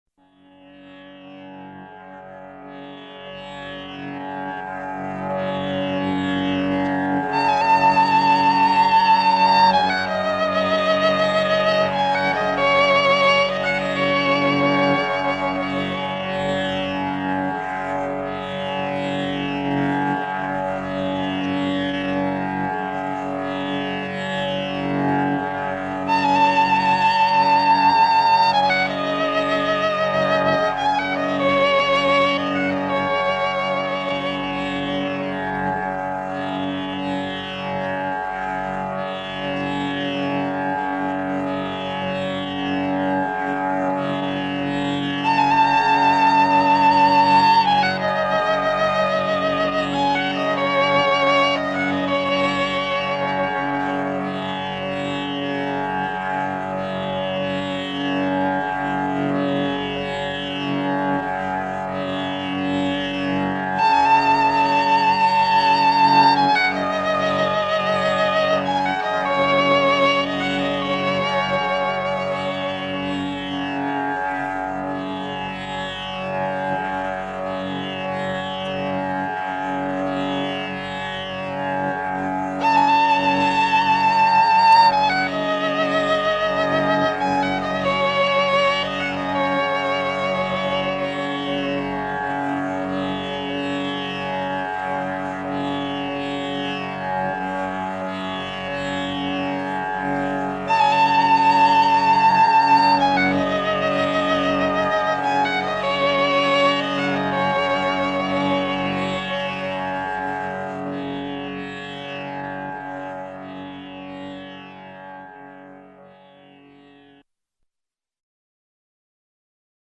The tune is based on Raga Shivaranjini.